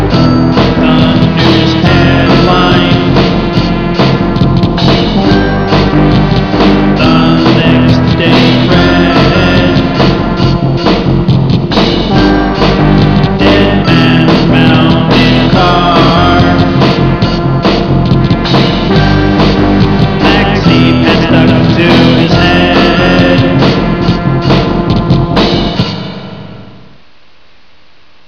experimental, strange,and exotic sound